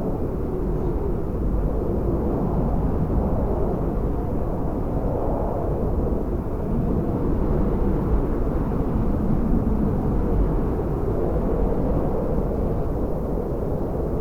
wind.ogg